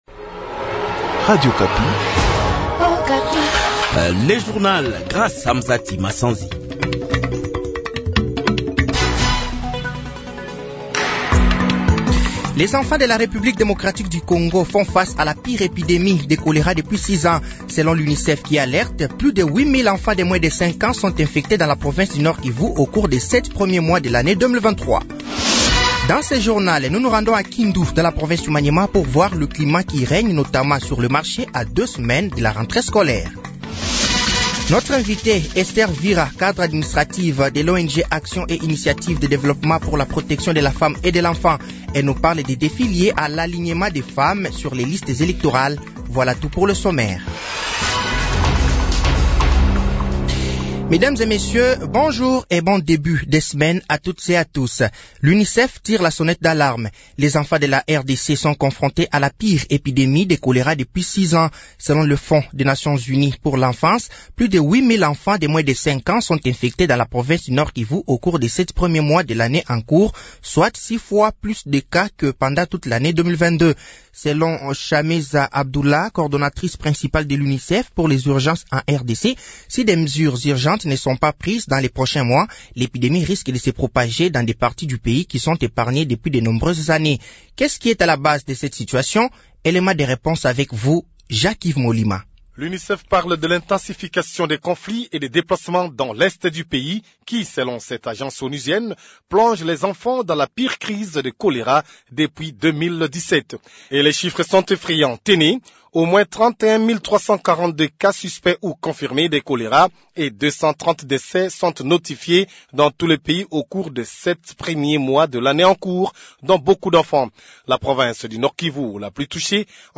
Journal français de 6h de ce lundi 21 août 2023